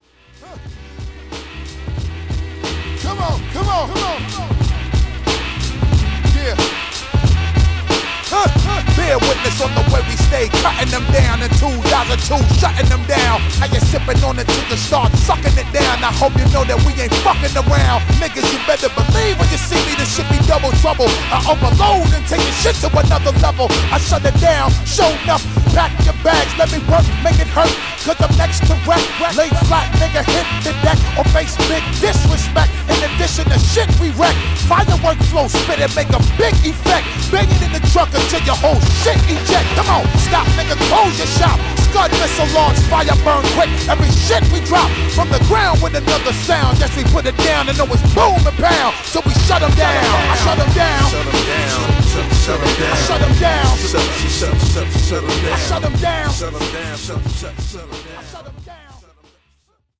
HIP HOP / R&B